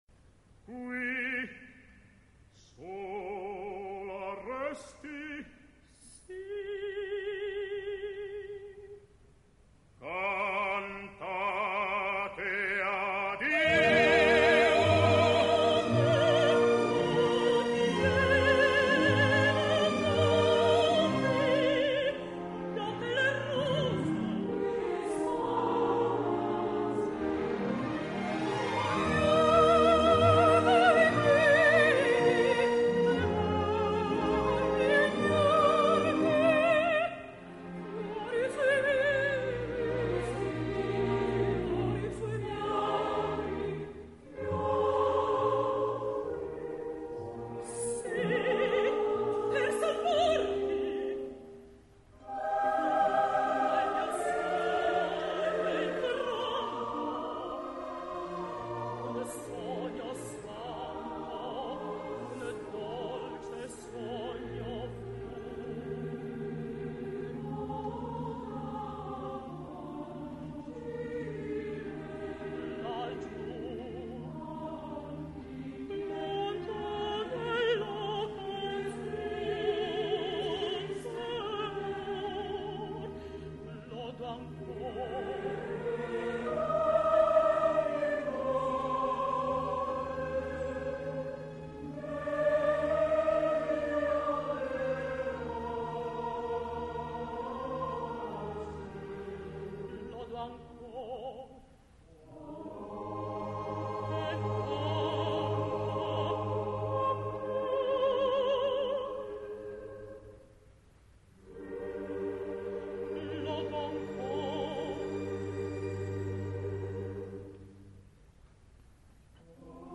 »melodramma / Melodram«
Dir.: G.Gavazzeni - Turin RAI
Fanuèl, Rubria, Perside & coro —› Qui sola resti?...
Rubria [Mezzo]
Fanuèl [Bariton]
Perside [Sopran]